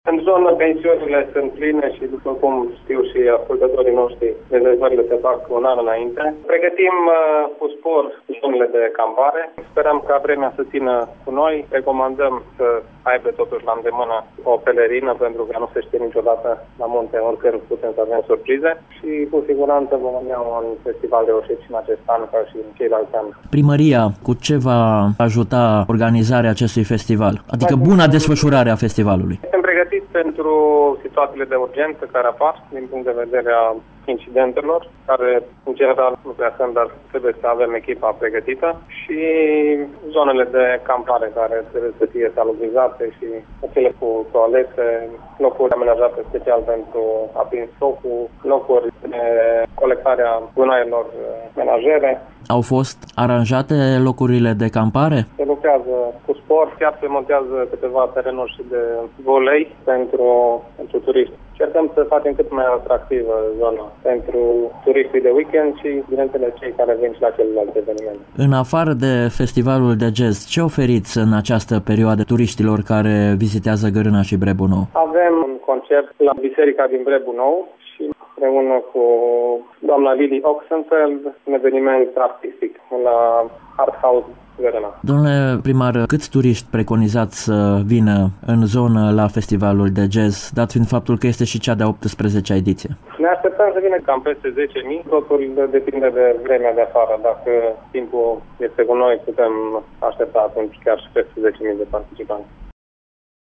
în dialog cu primarul comunei Brebu Nou, Iosif Gabriel Bordea: